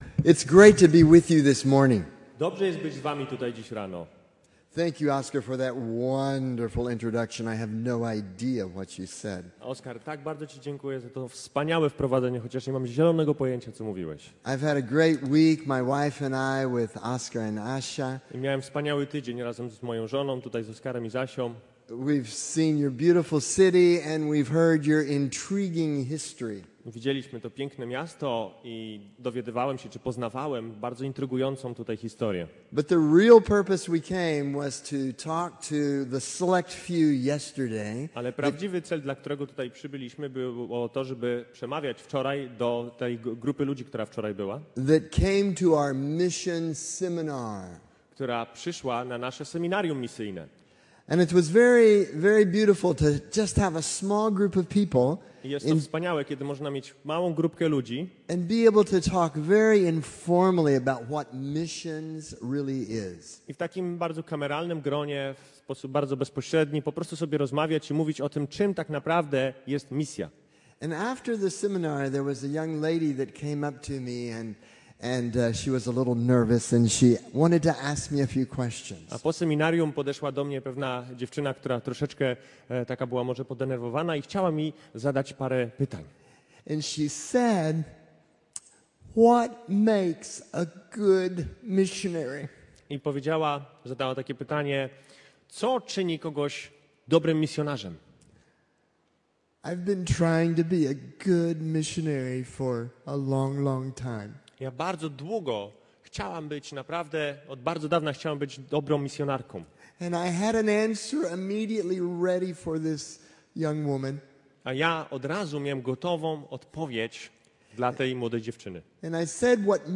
Kaznodzieja